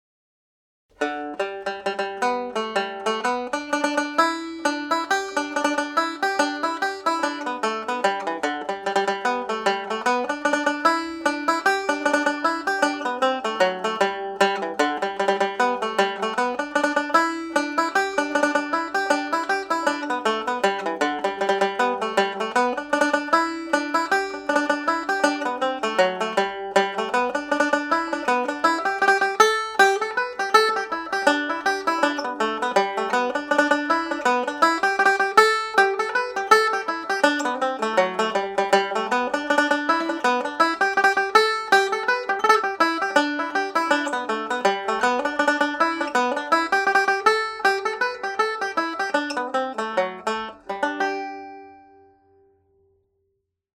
complete tune played a little faster with triplets added